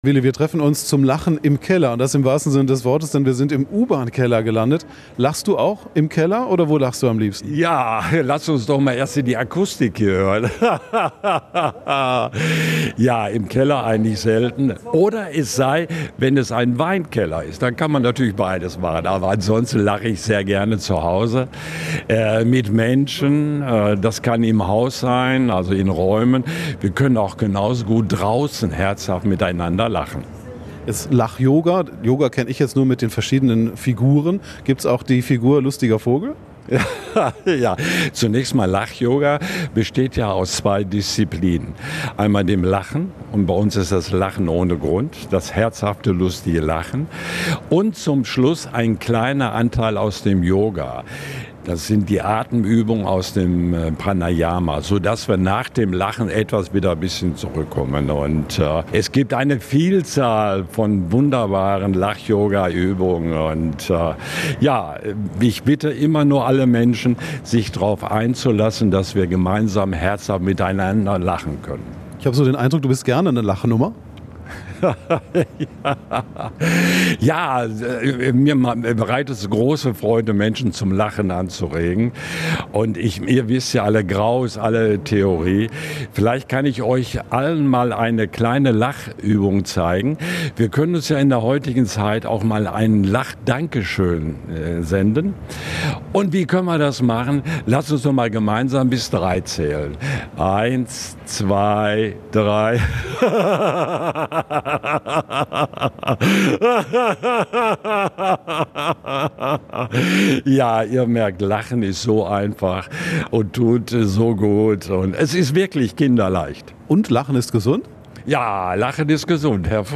Essen lacht und das steckt an ...
Zum Lachen in den U-Bahn-Keller